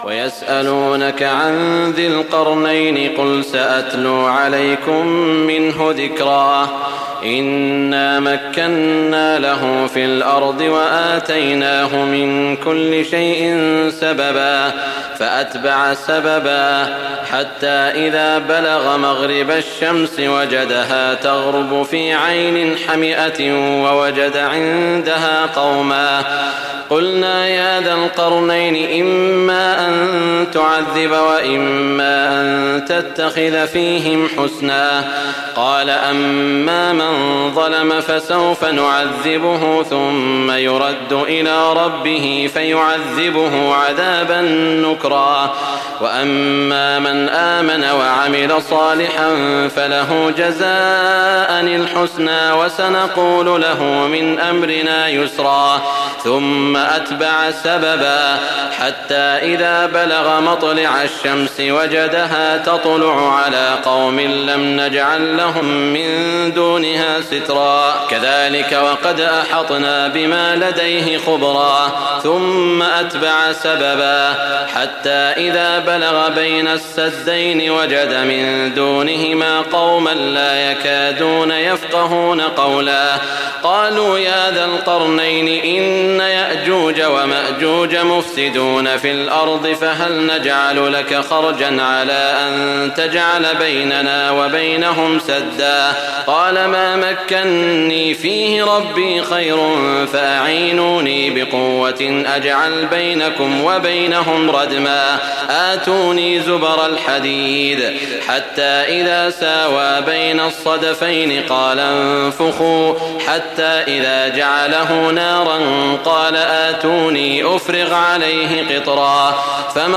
تراويح الليلة الخامسة عشر رمضان 1419هـ من سورتي الكهف (83-110) و مريم كاملة Taraweeh 15 st night Ramadan 1419H from Surah Al-Kahf and Maryam > تراويح الحرم المكي عام 1419 🕋 > التراويح - تلاوات الحرمين